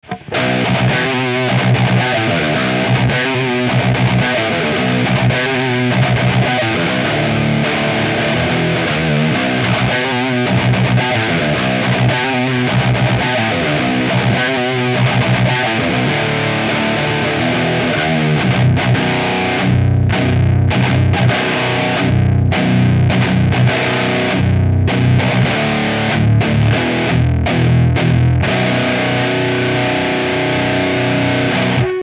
- Canal lead, Fat 2, Depth 0, Presence 10 :
Les EQ sont à midi, et le gain du lead à 2-3 sur tous les samples.
Evil Eddie_Lead 4 V2 D0 P10.mp3